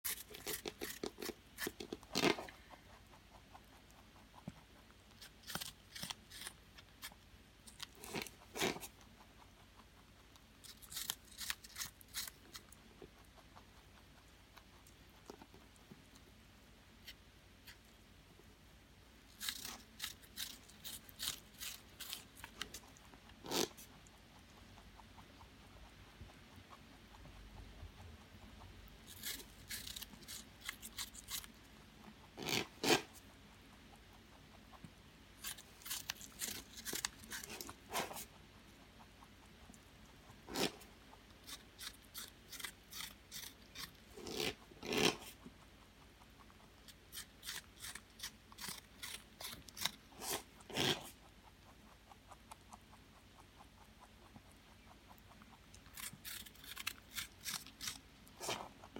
60 seconds of apple eating sound effects free download